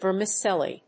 音節ver・mi・cel・li 発音記号・読み方
/v`ɚːmətʃéli(米国英語), v`əːmətʃéli(英国英語)/